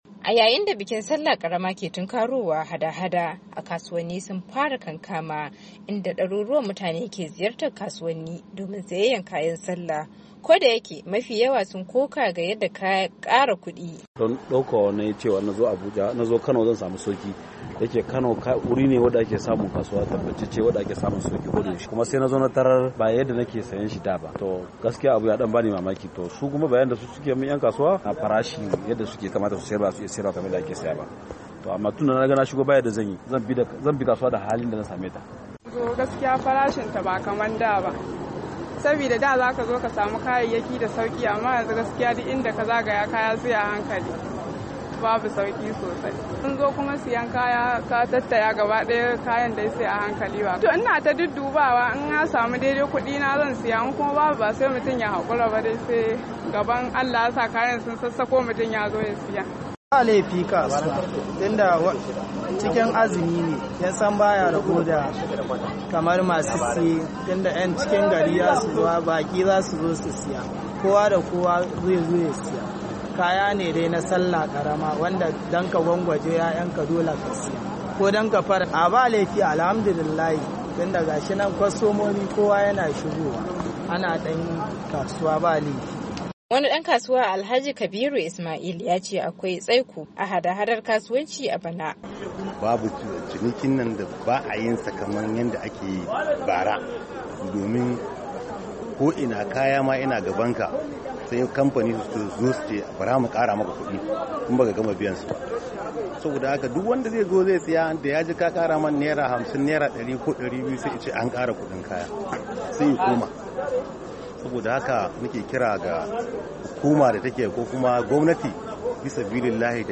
A daya bangaren kuma wani dan kasuwa ya koka ga rashin kudade a hannun masu sayen kaya, ya kuma nuna cewa ba ‘yan kasuwar bane ke kara kayayyaki, kamfanonin dake raba musu kayan ne ke kara musu kudu.